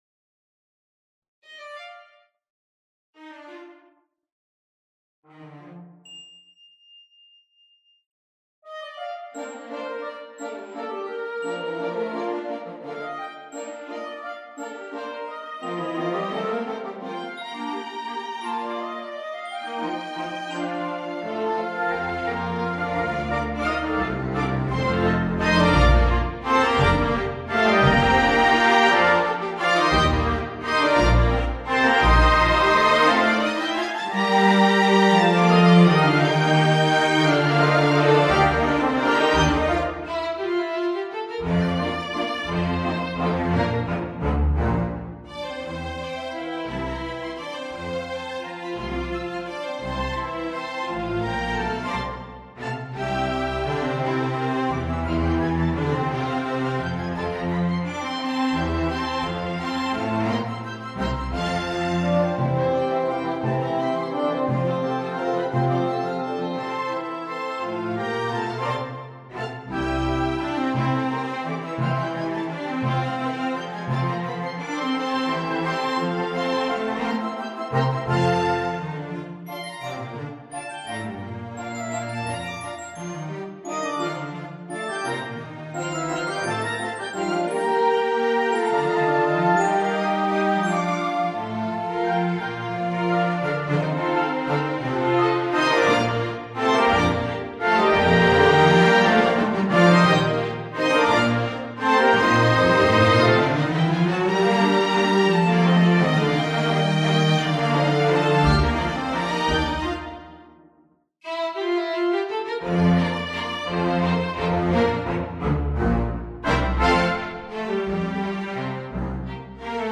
Genre Categories Waltzes; Dances; For strings; Scores featuring string ensemble
Key A-flat major
Piece Style Romantic
Instrumentation strings
Finale and Noteperformer rendition of Sentimental Waltz for full orchestra
The overall structure is A-B-A. The first section depicts a very Tchaikovsky-esque ballroom scene with rich harmonies that support a lilting melody. The bass line starts out as a bit of a tease but finally settles into the traditional "um" that the inner voices bounce off of with their "pa pas" that we expect in a waltz.
After this, we return to a recap of the first section with all the melodies and harmonies intact, with a few changes in the orchestration, and a little added pause before the final cadence.